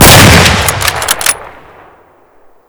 shoot_1.ogg